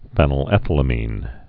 (fĕnəl-ĕthə-lə-mēn, -lămən, fēnəl-)